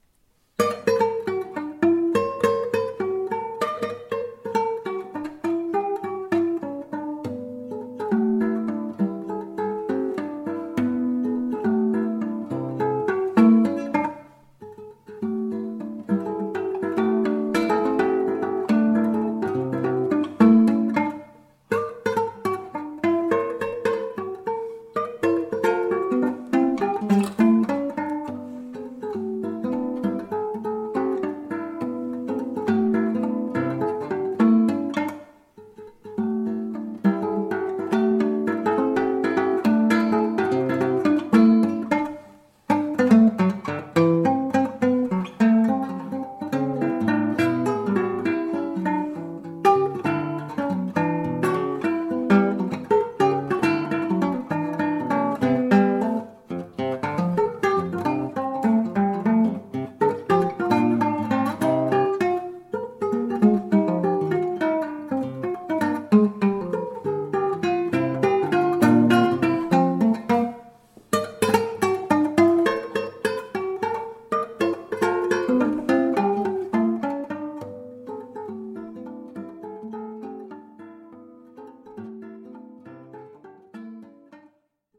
Spectacle musical pour les enfants de 6 mois à 5 ans.
Extrait musical: African sketch - Dusan Bogdanovic